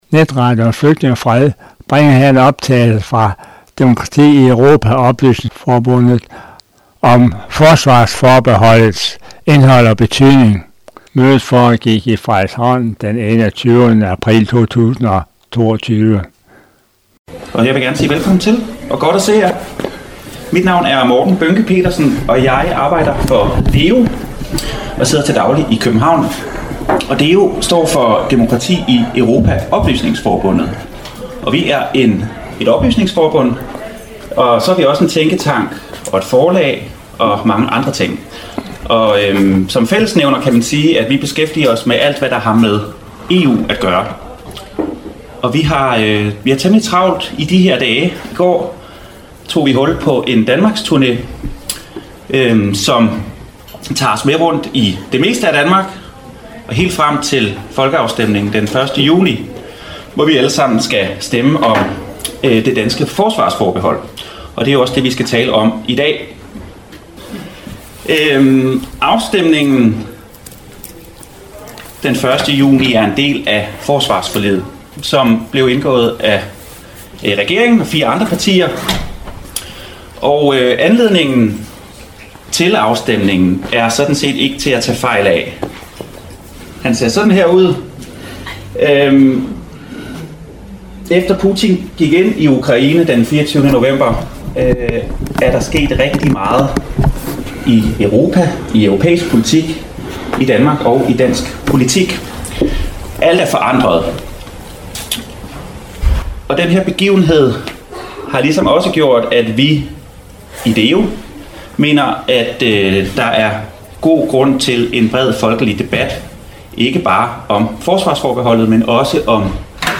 Netradioen Flygtninge og Fred bringer her en optagelse fra Demokrati i Europa- Oplysningsforbundet om forsvarsforbeholdets indhold og betydning
Efter oplæggene fulgte en debat med mødedeltagerne på baggrund af disses spørgsmål og problematiseringer